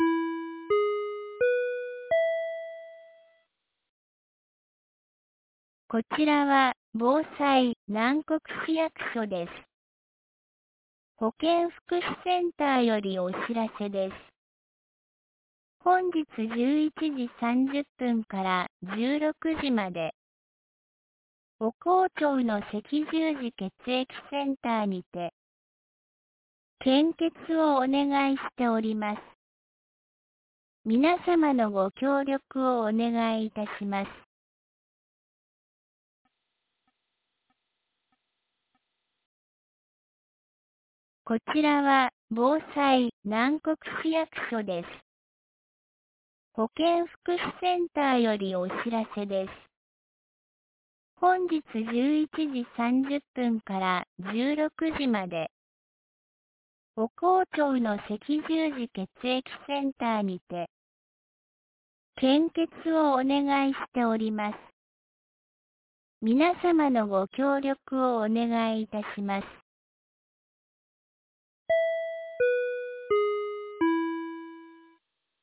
2026年04月02日 10時01分に、南国市より放送がありました。